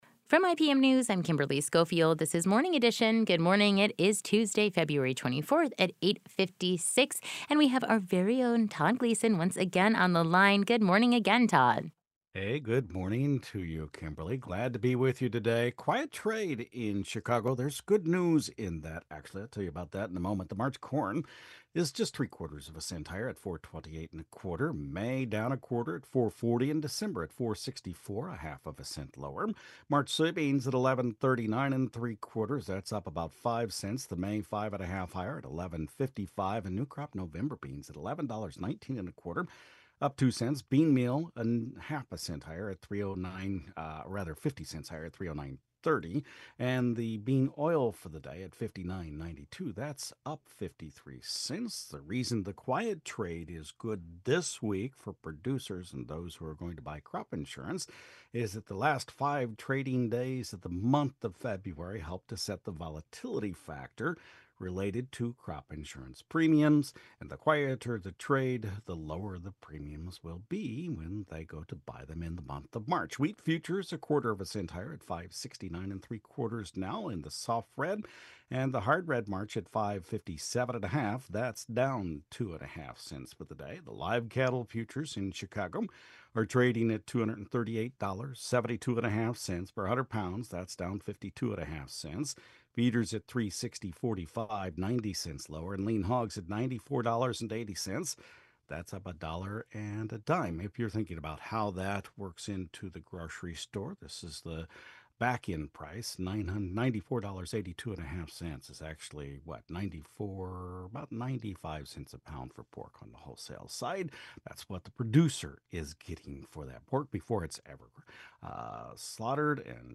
Opening Market Report